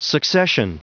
Prononciation du mot succession en anglais (fichier audio)
Prononciation du mot : succession